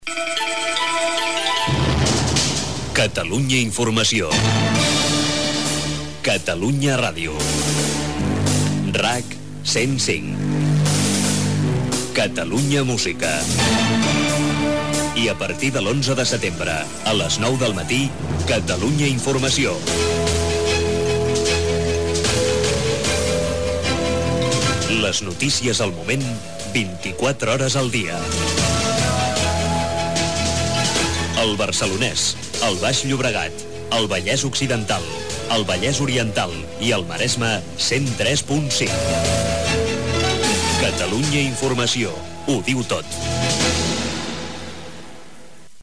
Indicatiu amb la identificació i algunes de les freqüències d'emissió en els dies d'emissió en proves, abans de la inauguració l'11 de setembre de 1992
FM